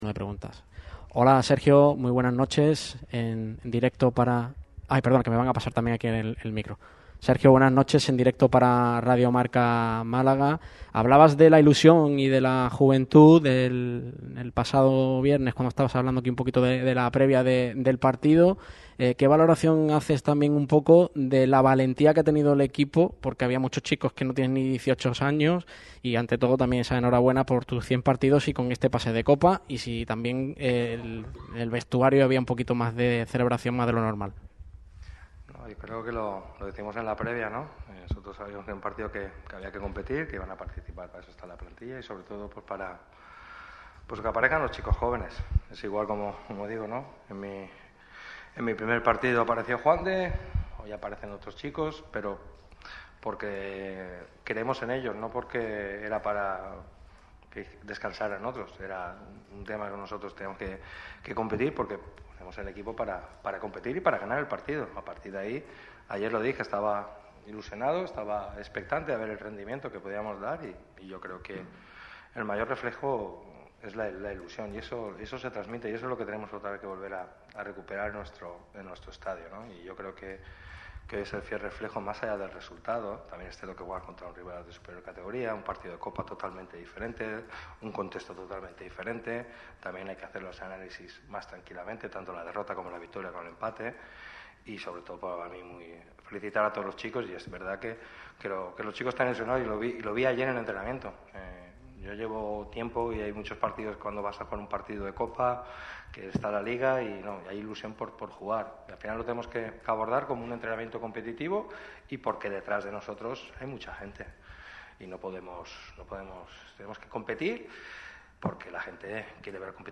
El técnico del Málaga CF ha comparecido ante los medios tras la victoria de los boquerones sobre el Eldense, lo que supone la clasificación a una ronda más de Copa del Rey. Pellicer hace una valoración general del encuentro y habla sobre lo que le viene al Málaga en el futuro.